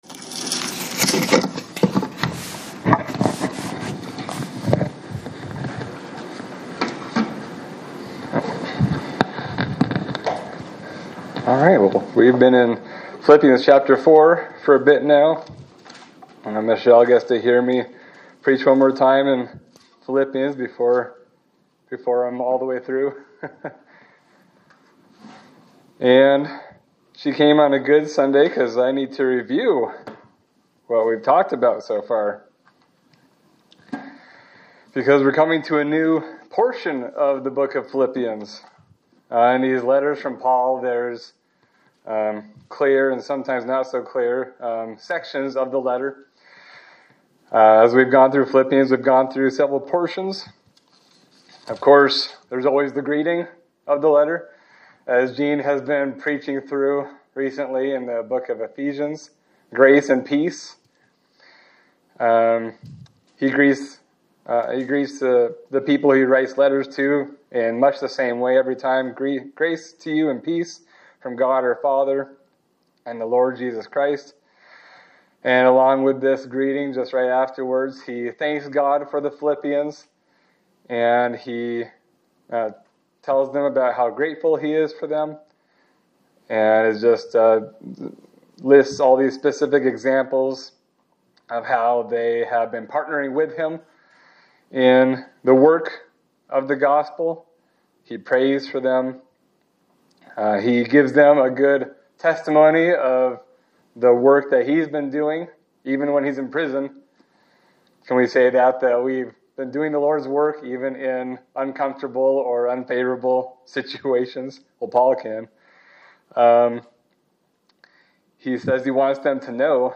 Sermon for March 22, 2026
Service Type: Sunday Service